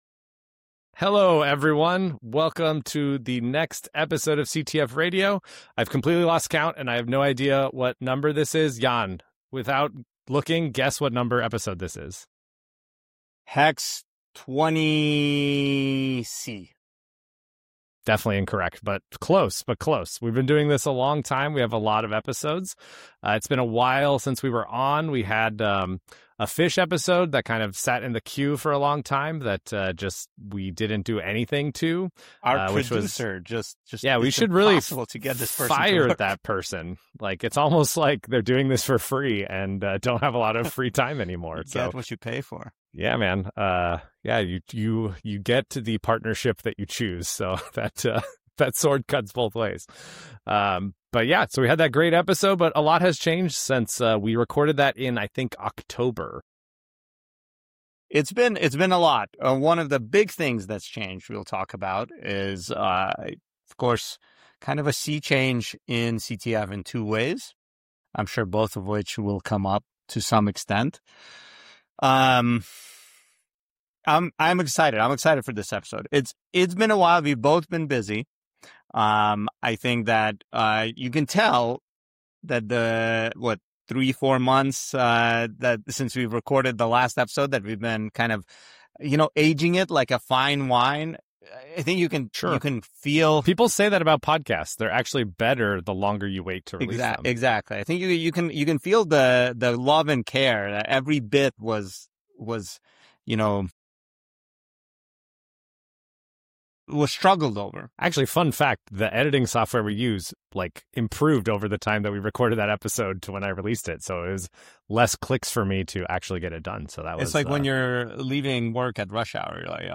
025 March 18, 2026 Chatting with NEW DEF CON CTF Organizers: Benevolent Bureau of Birds